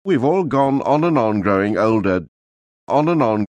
A good way to illustrate this distinction is with native speaker recordings of the common phrase on and on, /ɒn ən ɒn/: